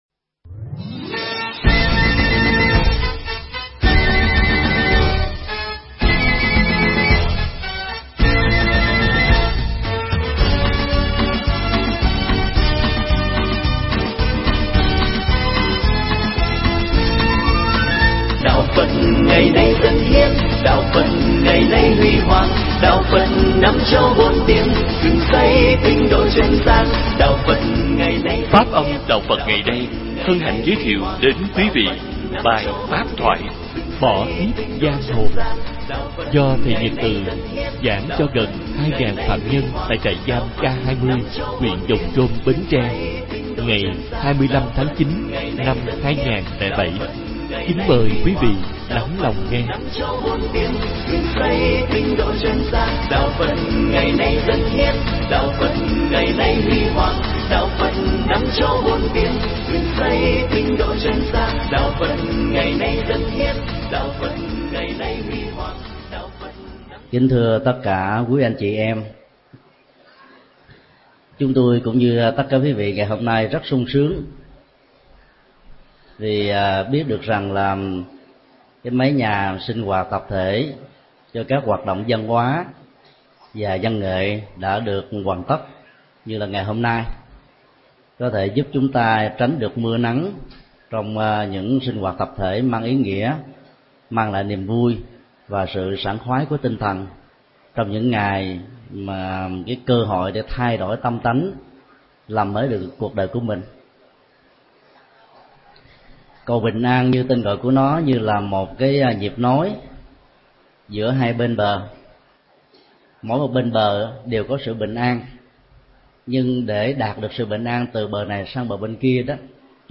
Tải mp3 Pháp thoại Bỏ kiếp giang hồ được thầy Thích Nhật Từ thuyết giảng cho gần 2000 phạm nhân tại trại giam K.20, huyện Giòng Trôm, Bến Tre, ngày 25 tháng 09 năm 2007.